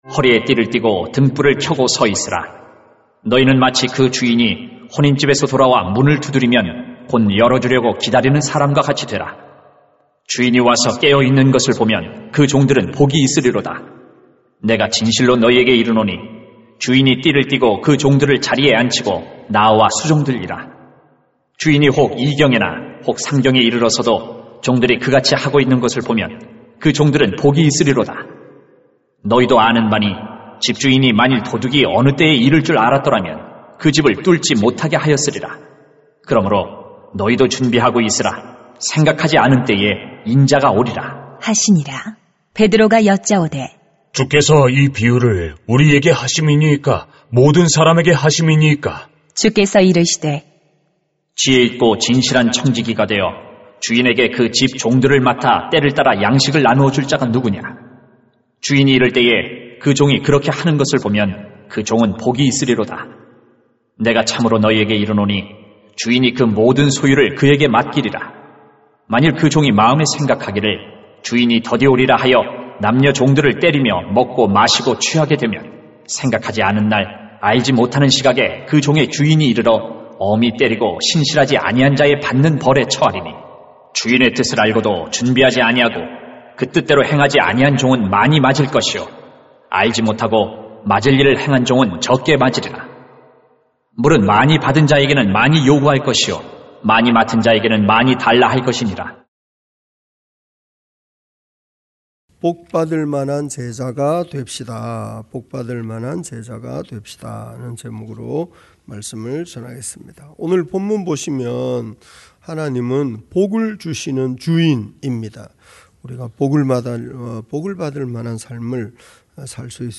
[눅 12:35-48] 복 받을 만한 제자가 됩시다 > 새벽기도회 | 전주제자교회